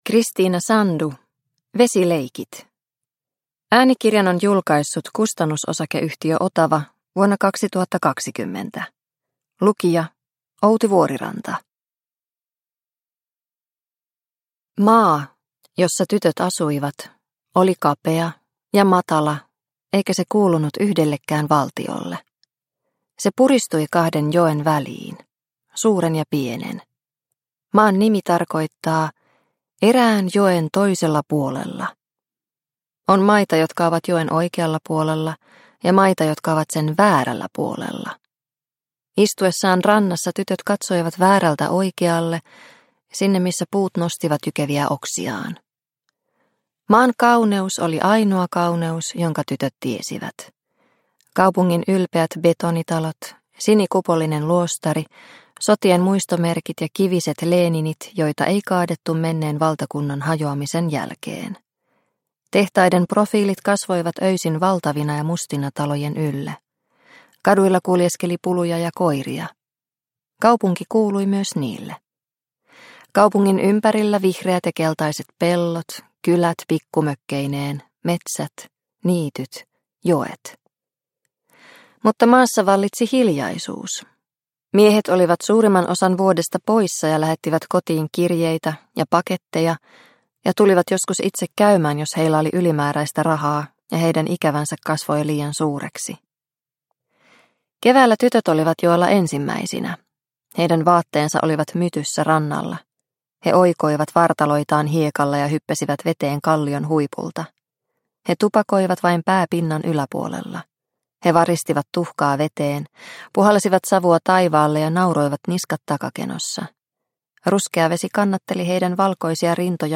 Vesileikit – Ljudbok – Laddas ner